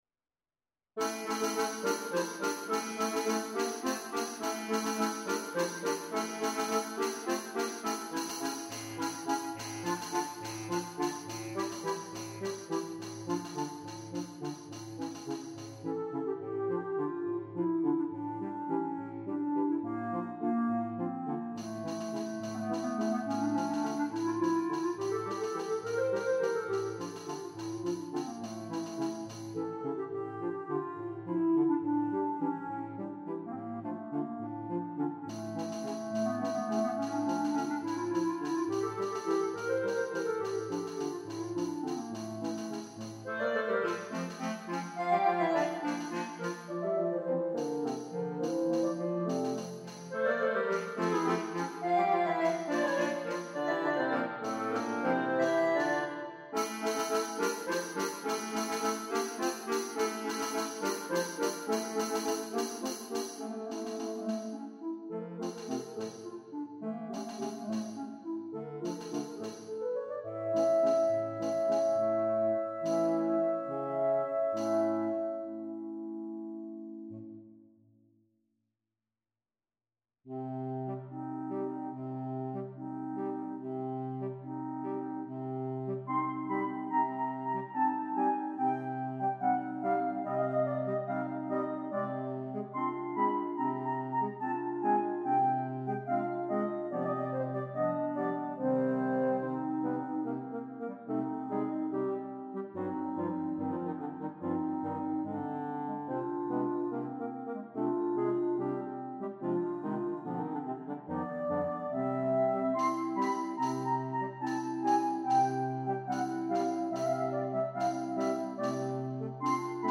Tambourine